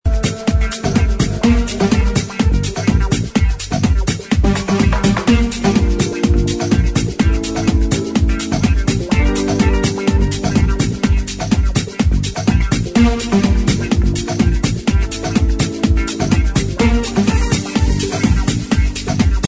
New Progressive is not IDed